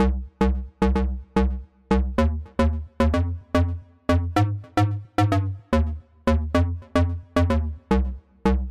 描述：一些设计用于现代俱乐部音乐的贝司件。短小的低音塞子，可作为组合使用
标签： 低音 俱乐部 合成器
声道立体声